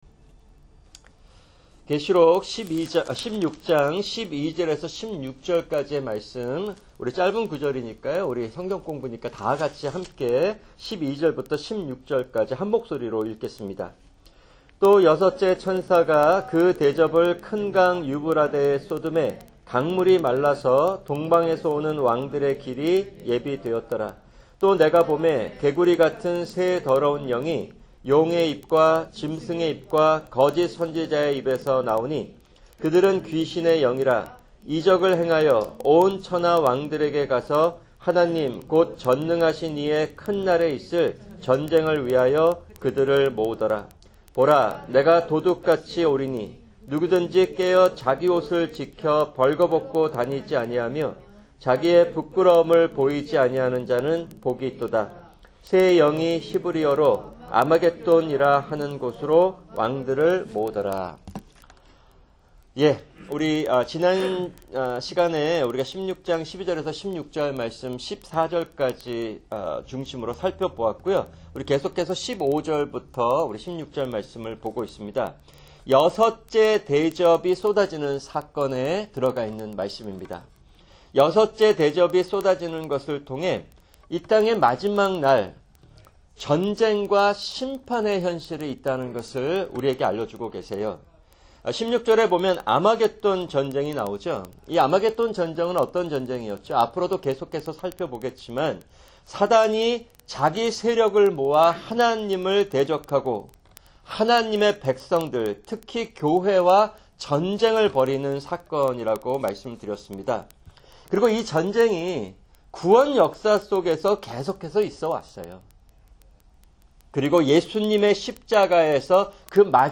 [금요 성경공부] 계시록 16:12-16(2)